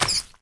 small_spider.ogg